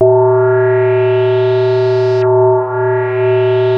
JUP 8 C5 9.wav